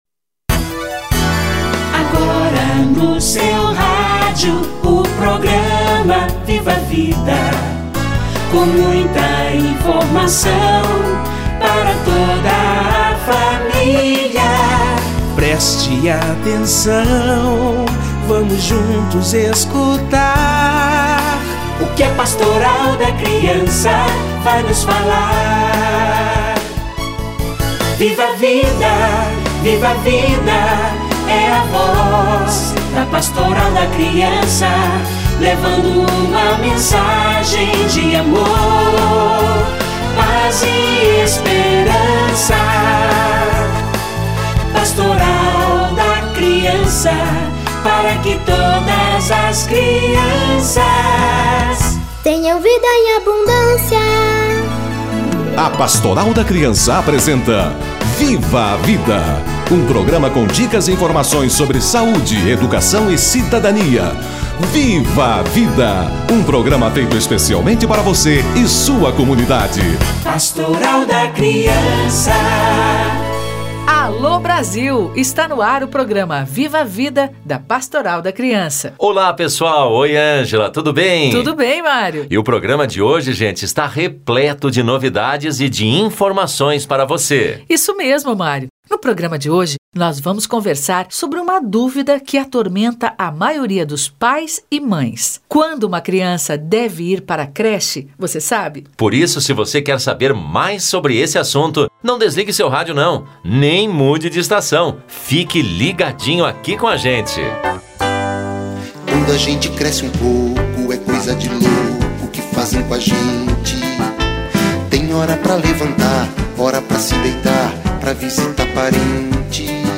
A hora de ir para o berçário ou creche - Entrevista